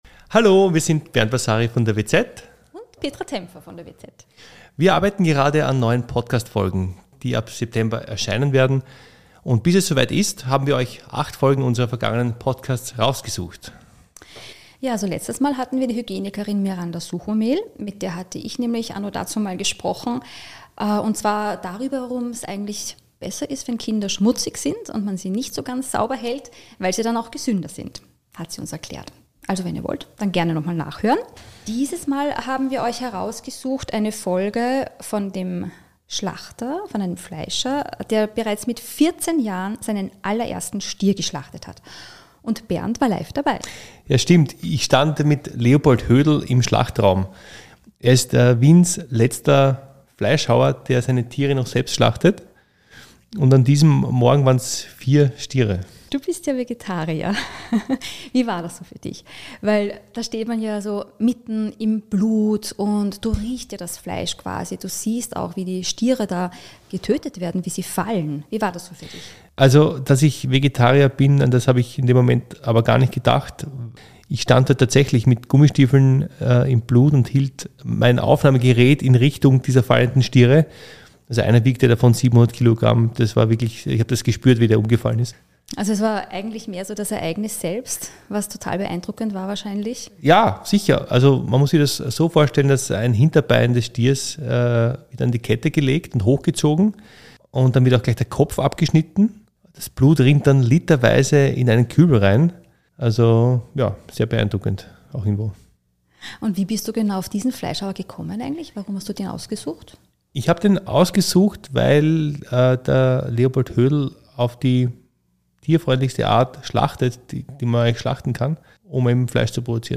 Live im Schlachtraum (Best-of) ~ Weiter gedacht - der Podcast der WZ Podcast